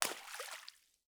SPLASH_Subtle_mono.wav